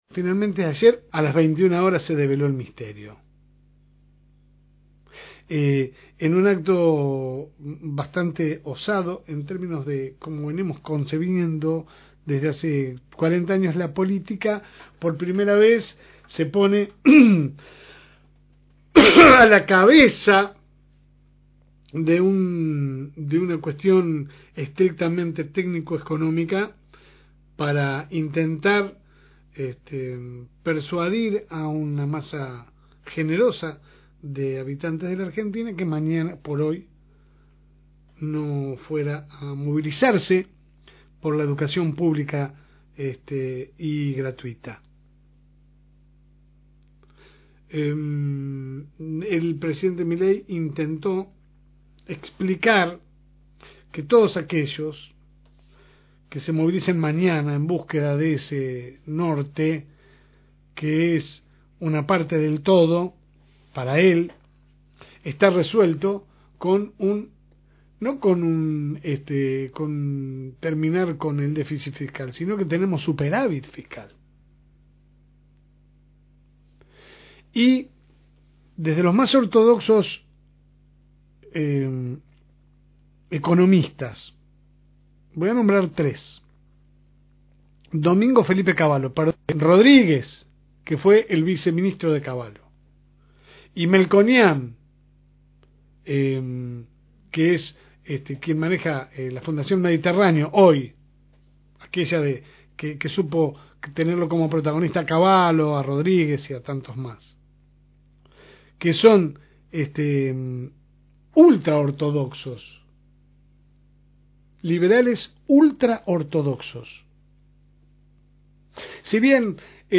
AUDIO – Editorial – FM Reencuentro